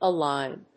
音節a・lign 発音記号・読み方
/əlάɪn(米国英語), əˈlaɪn(英国英語)/